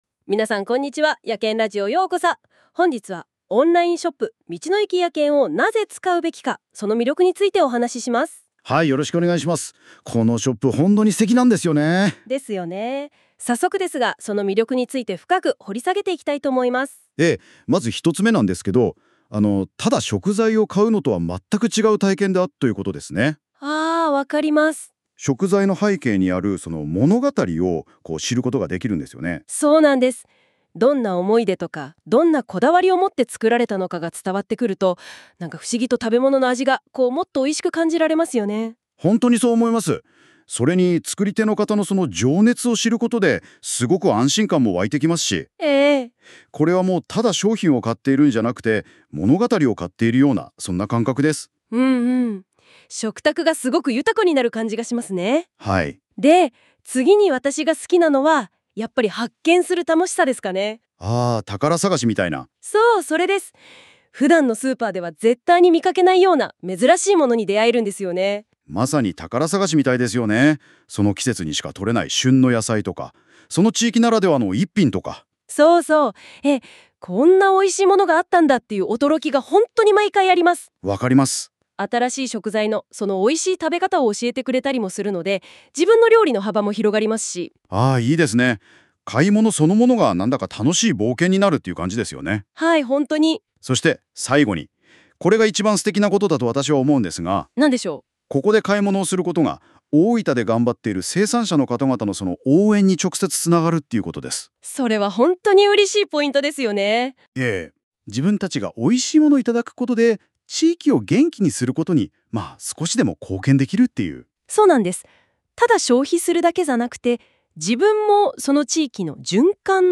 「やけんラジオ」にて、私たちが大切にしている「食材の背景にある物語」について特集されました。単に食材を揃える場所ではなく、生産者一人ひとりの情熱やこだわりを知ることで、いつもの食事がもっと美味しく、安心できるものに変わる理由を詳しくお話ししています 。